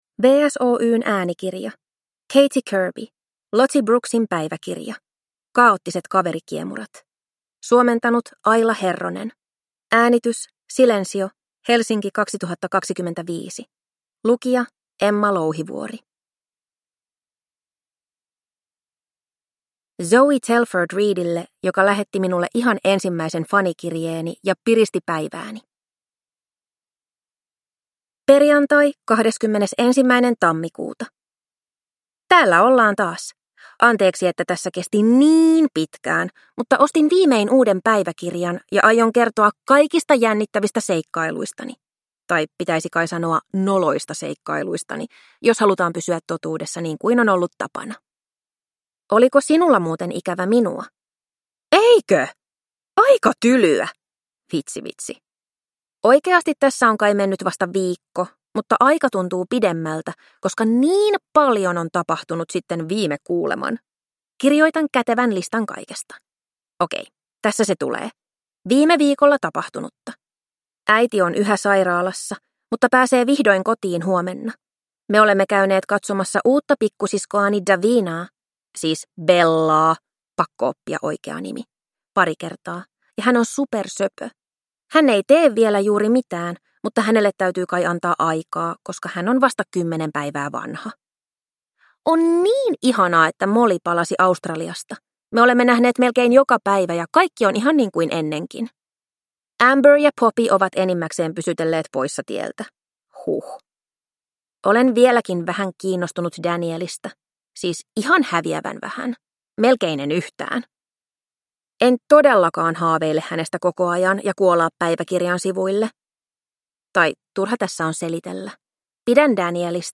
Lottie Brooksin päiväkirja: Kaoottiset kaverikiemurat – Ljudbok